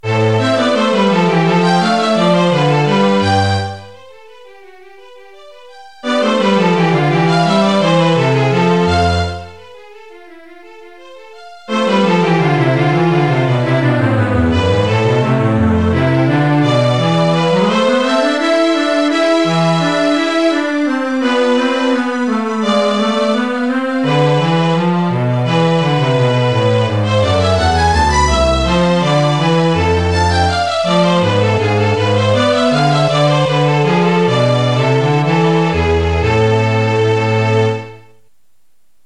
Звуки, которые в pro Audio 9, неприятны для слуха, например, скрипка похожа на гармошку.
Я прикрепляю файл (midi я перевёлв audio). Ну, очень механистично звучит.